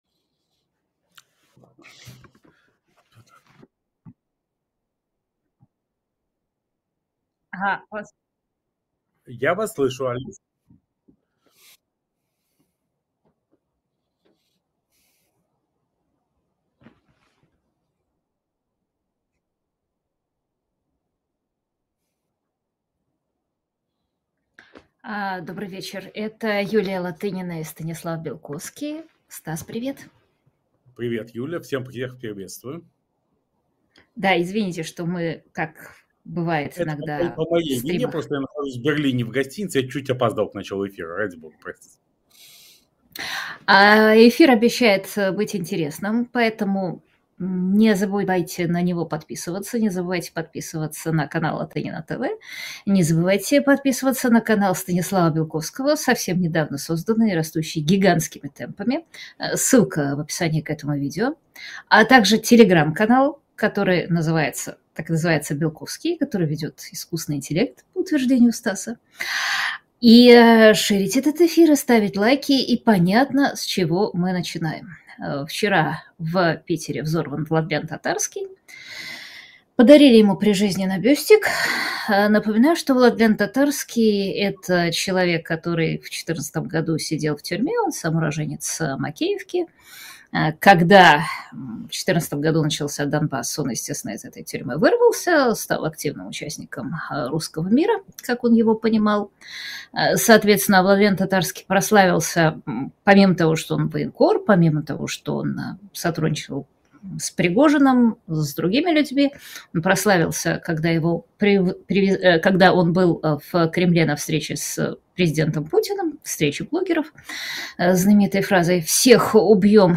Интервью Латынина и Белковский Скачать Подписаться на Yulia Latynina Поддержать канал Latynina TV Ю. ЛАТЫНИНА: Добрый вечер.